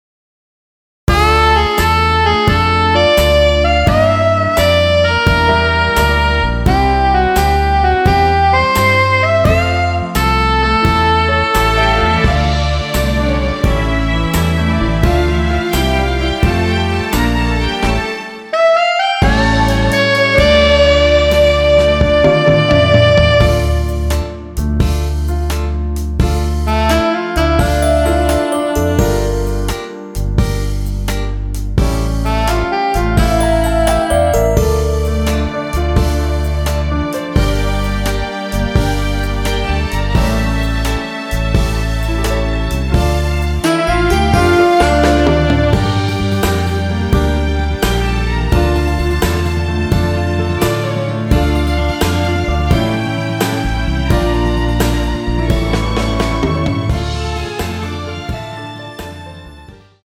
원키에서(-1)내린 MR입니다.
Dm
앞부분30초, 뒷부분30초씩 편집해서 올려 드리고 있습니다.
중간에 음이 끈어지고 다시 나오는 이유는